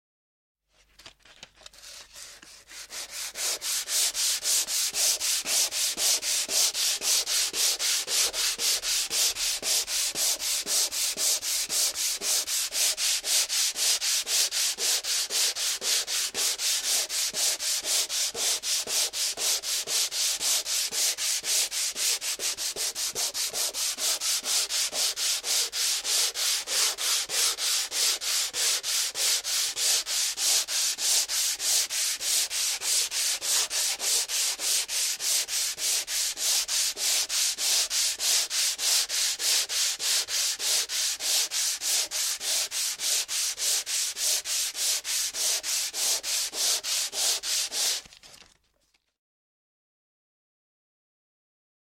На этой странице собраны звуки штукатурки: от мягкого шуршания до резких скребущих движений.
Звук шлифовальной бумаги